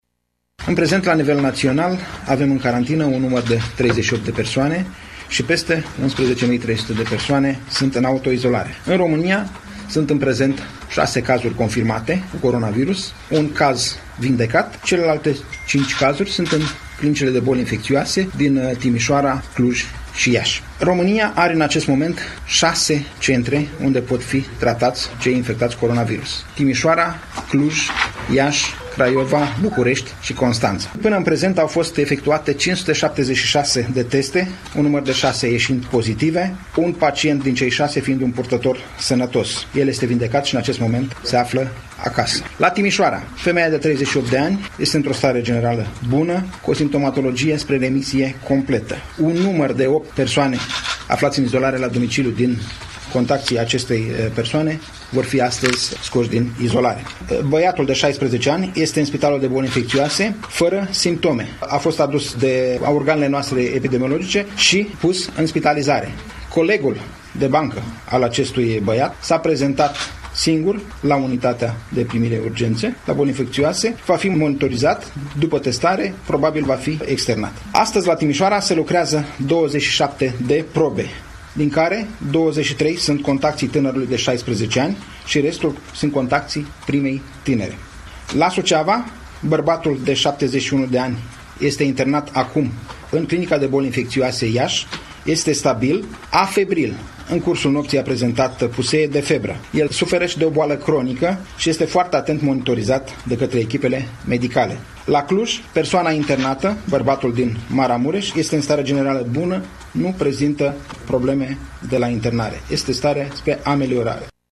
Secretarul de stat în Ministerul Sănătății, Nelu Tătaru, a făcut în urmă cu puțin timp precizări privind starea de sănătate a pacienților confirmați cu coronavirus: